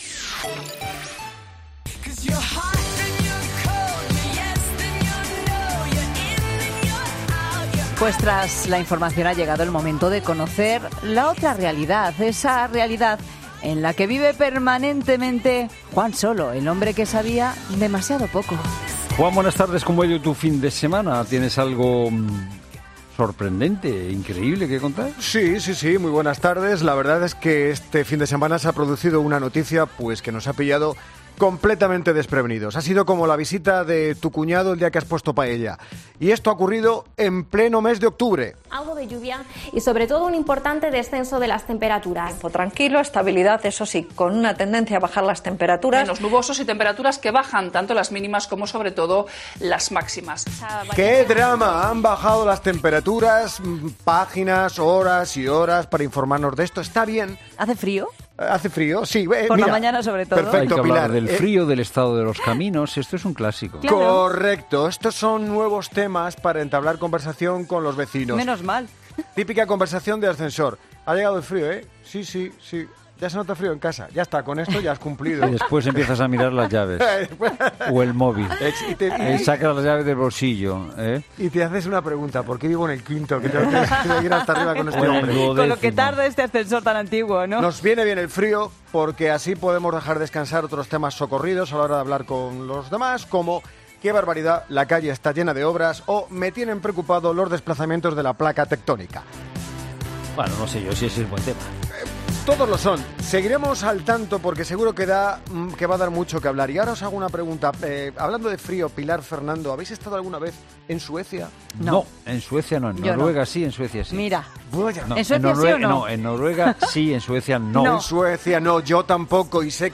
Humor: 'El hombre que sabía demasiado poco'